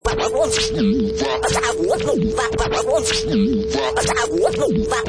Wav: Vinyl Crazy Scratch 95 bpm 1
Professional killer vinyl scratch perfect for sampling, mixing, music production, timed to 95 beats per minute
Product Info: 48k 24bit Stereo
Category: Musical Instruments / Turntables
Try preview above (pink tone added for copyright).
Vinyl_Crazy_Scratch_95_bpm_1.mp3